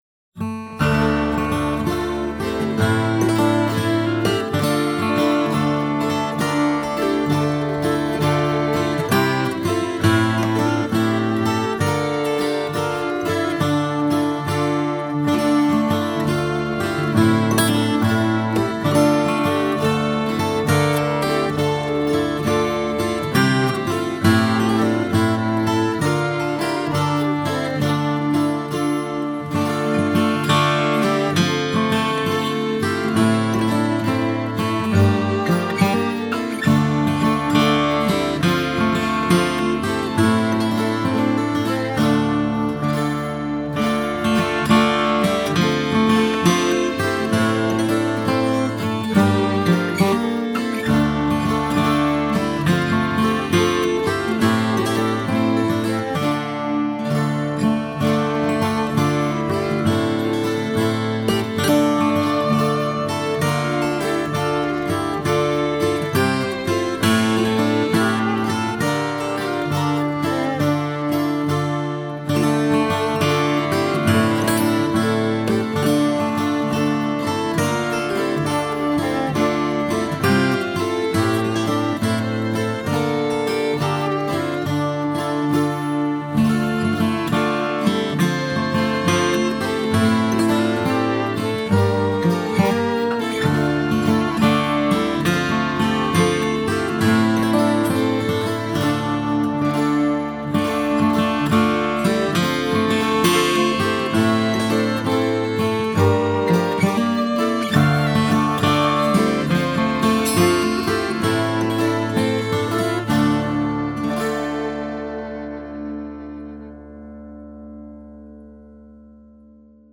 (Instrumental Tune, Trad. Arr.)